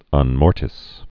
(ŭn-môrtĭs)